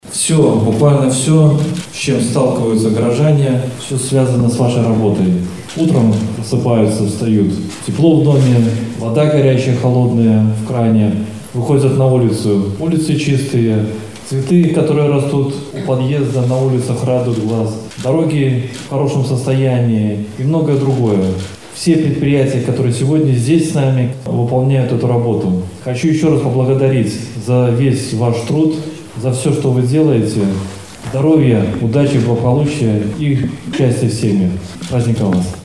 В преддверии значимой даты на базе музыкальной школы искусств состоялась торжественная церемония чествования лучших специалистов.
Они дарят нам комфорт и уют, отметил в своем поздравлении заместитель председателя горисполкома Дмитрий Тихно.